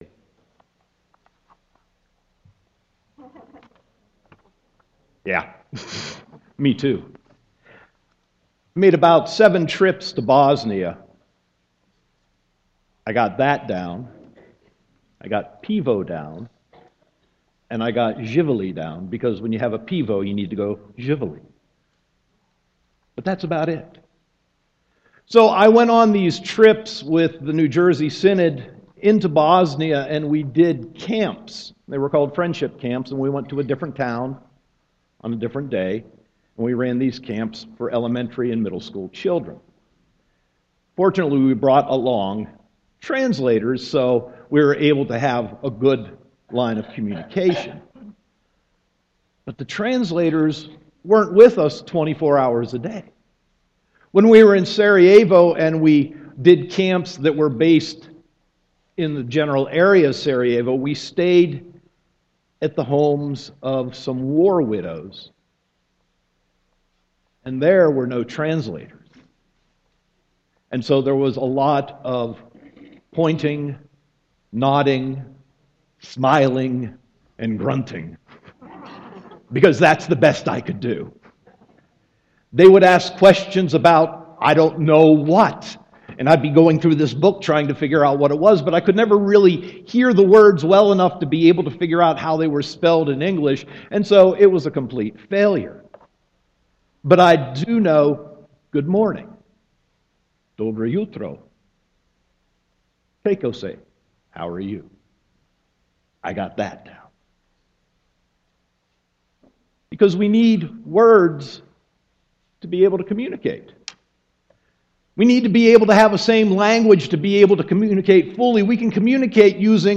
Sermon 3.1.2015